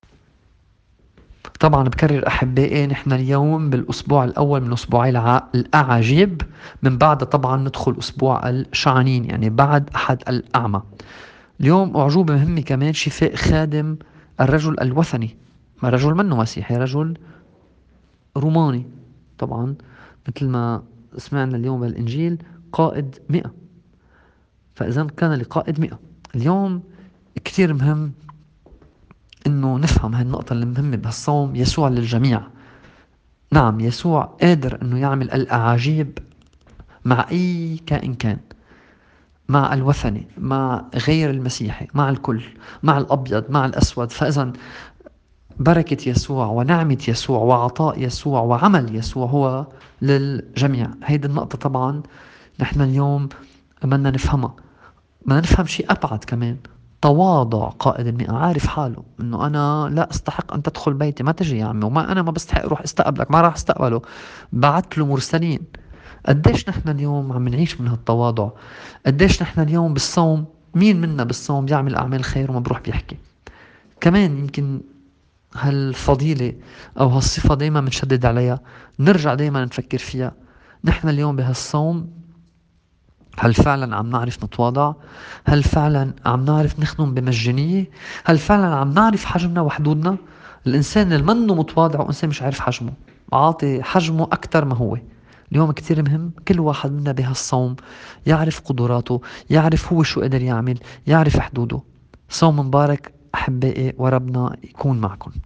تأمّل في إنجيل اليوم